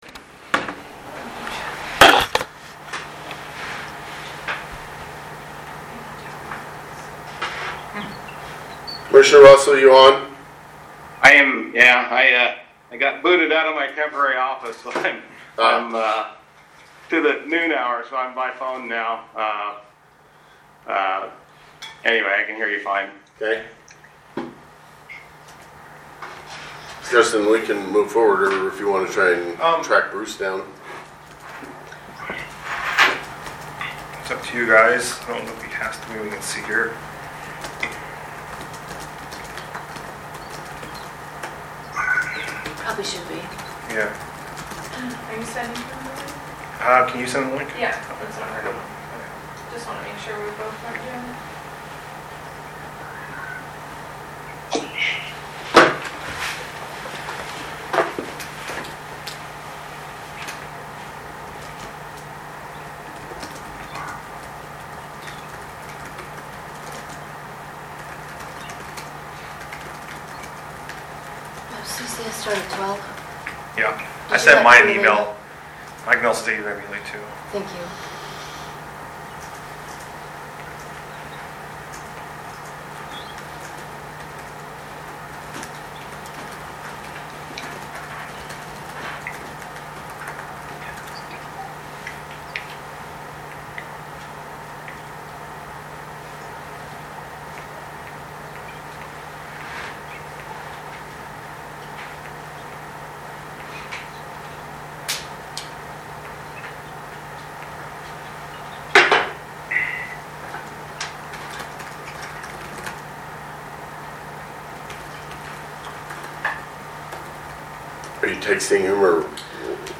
Special Meeting of the Board of Commissioners
Morrow County Bartholomew Building